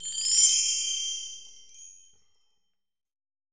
FRBELLTRE3-S.WAV